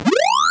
pacghosteat.wav